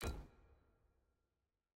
sfx_ui_research_panel_disabled.ogg